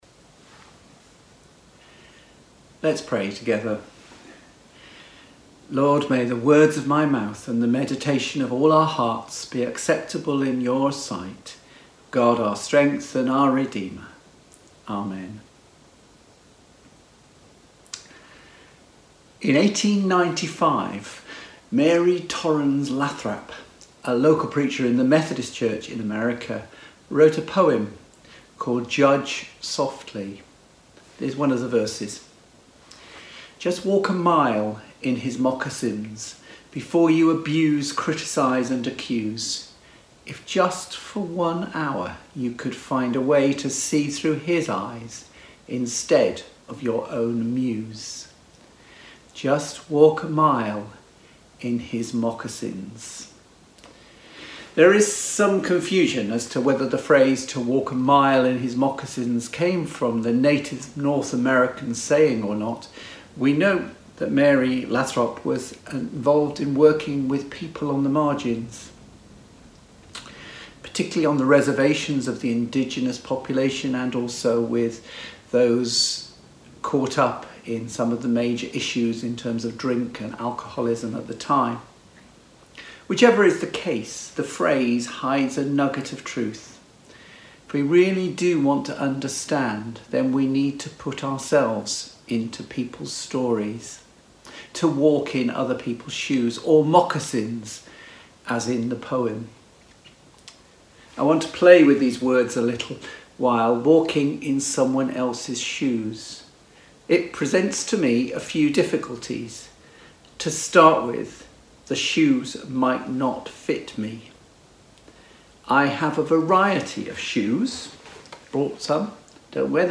latestsermon-4.mp3